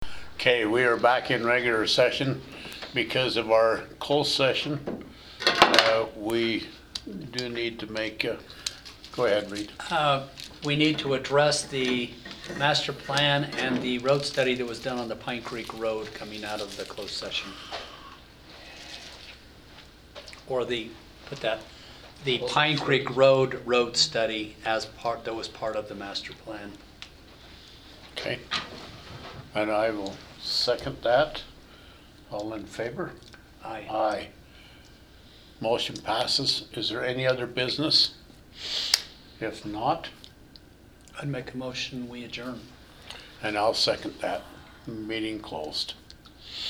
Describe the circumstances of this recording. Sanpete County Commission Meeting One or more of the Commissioners can participate by means of a telephonic or telecommunications conference.